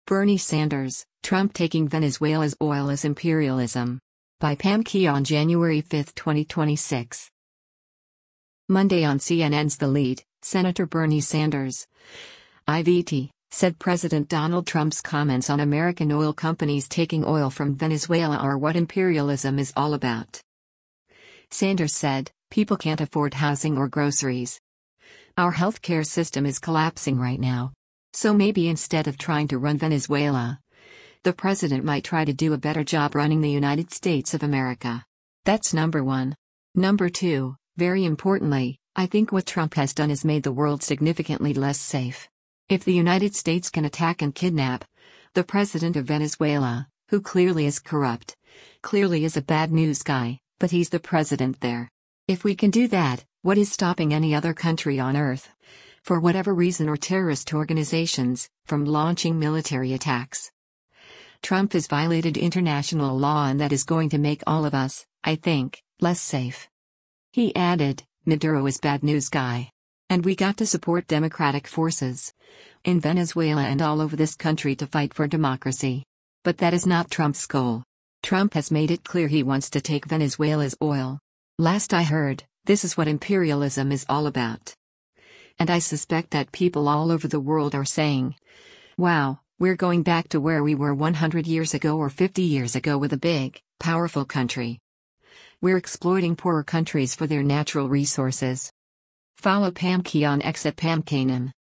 Monday on CNN’s “The Lead,” Sen. Bernie Sanders (I-VT) said President Donald Trump’s comments on American oil companies taking oil from Venezuela are “what imperialism is all about.”